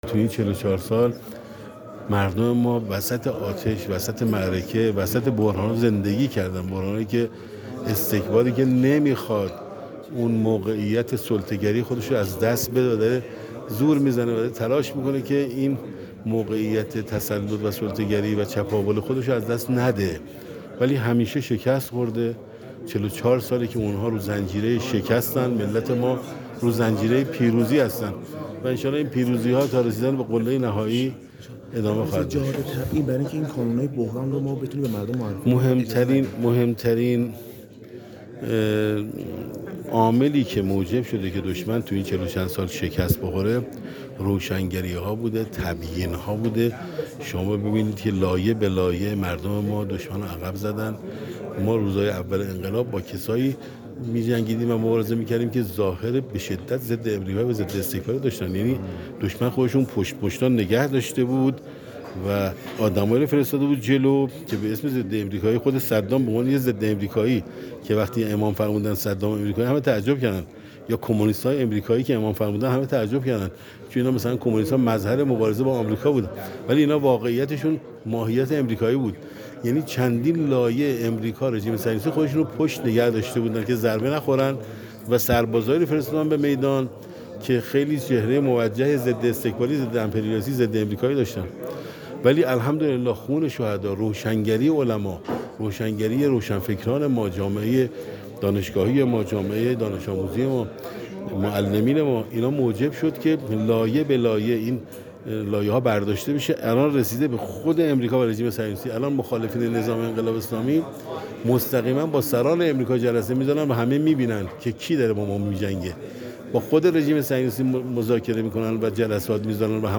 سردار نقدی در گفت‌وگو با ایکنا تأکید کرد: